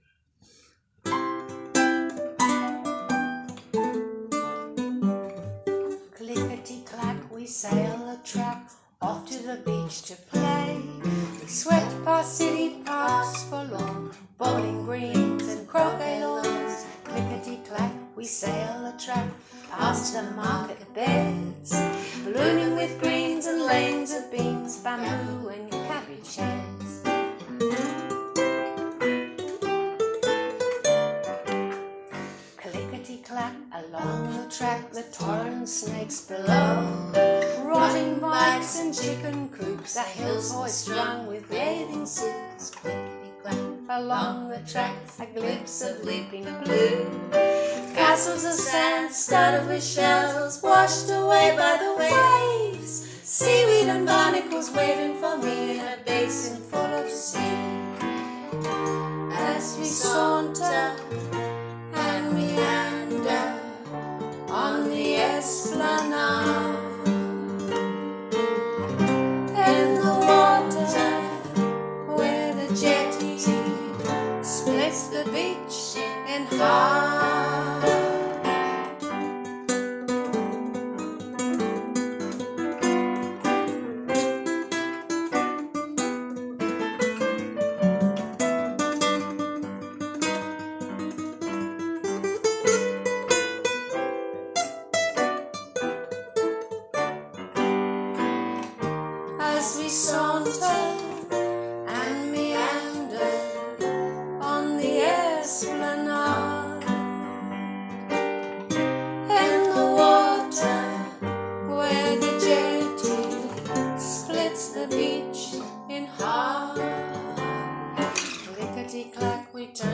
The Trio’s gentle style immerses the audience into a time when our lives felt more connected to nature and our loved ones.